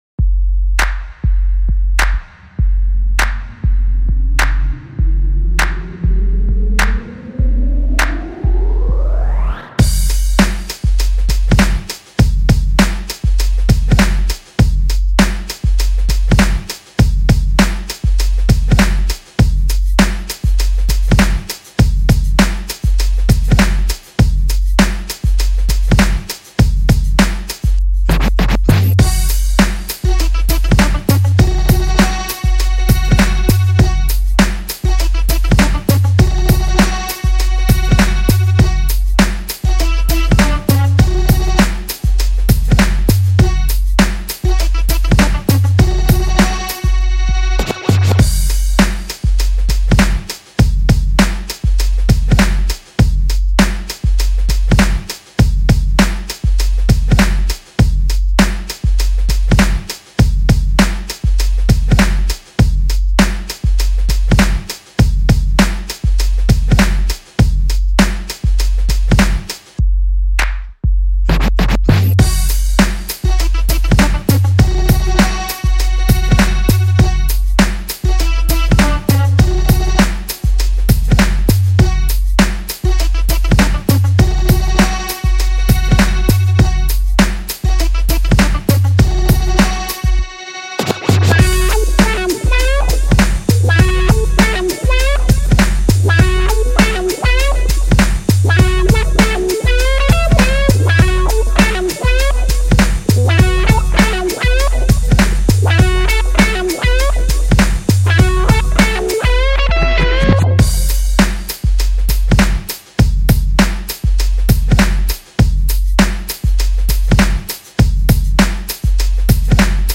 детской песни